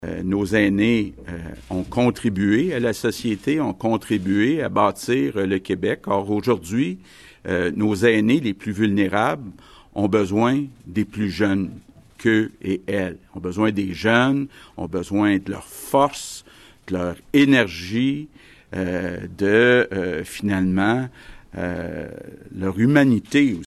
En point de presse mardi avant-midi, le premier ministre François Legault s’est adressé spécifiquement aux jeunes travailleurs :